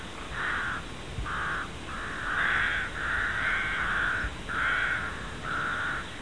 grach-corvus-frugilegus.mp3